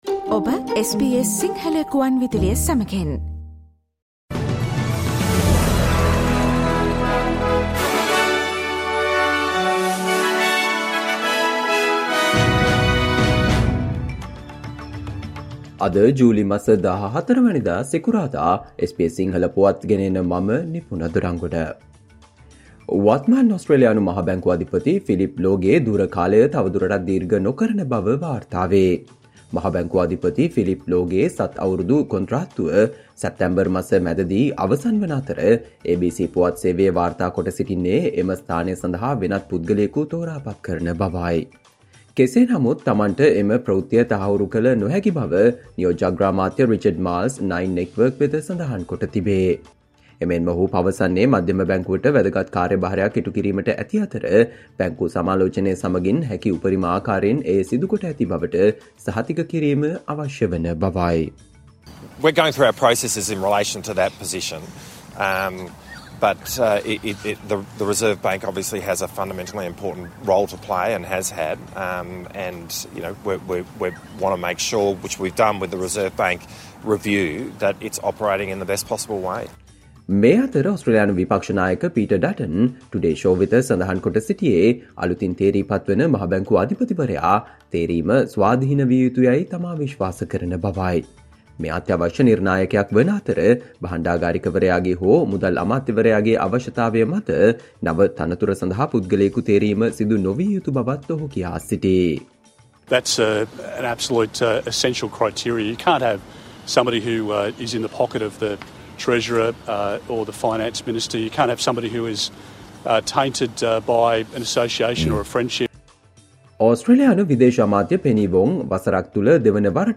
ඕස්ට්‍රේලියාවේ පුවත් සිංහලෙන්, විදෙස් සහ ක්‍රීඩා පුවත් කෙටියෙන් - සවන්දෙන්න, අද - 2023 ජූලි මස 14 වන සිකුරාදා SBS ගුවන්විදුලියේ ප්‍රවෘත්ති ප්‍රකාශයට